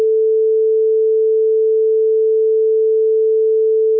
sinewave.wav